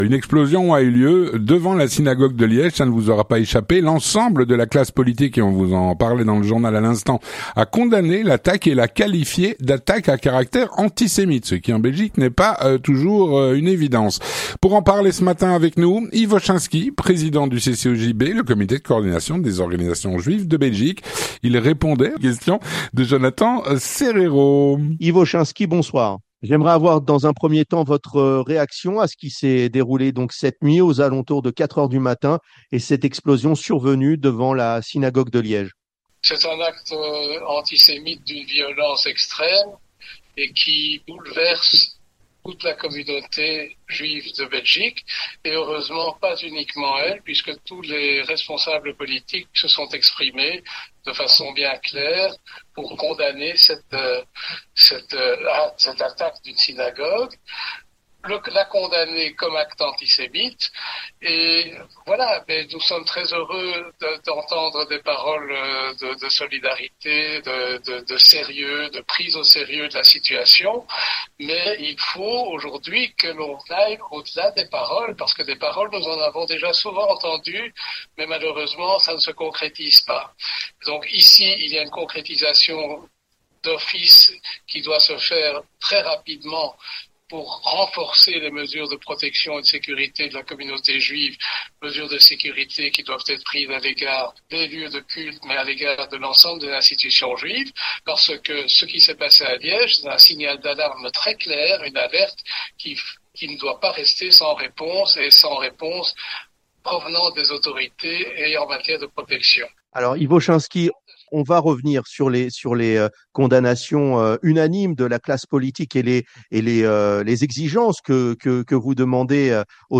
3. L'interview communautaire